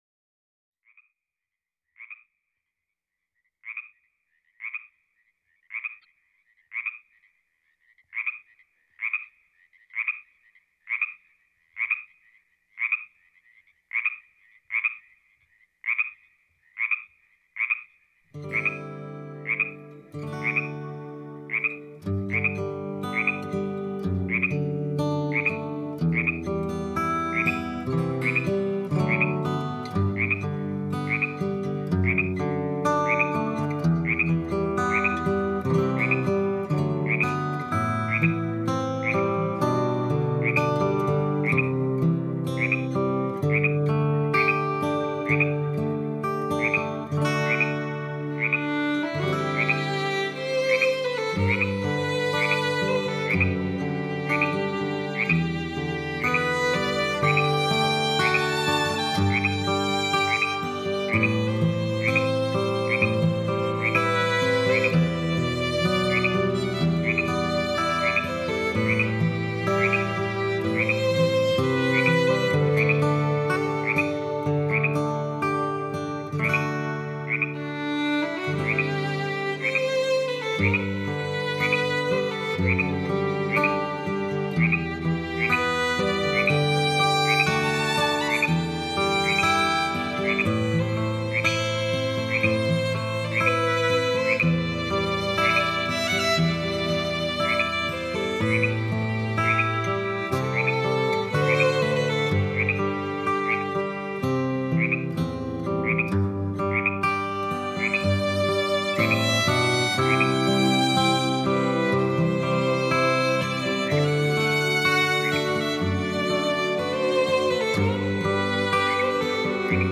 冥想类音乐5
冥想音乐能帮助我们放松身心，还能减轻压力，集中注意力......